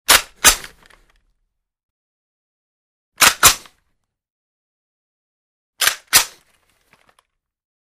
Звуки огнестрельного оружия
Перезарядка затвора винтовки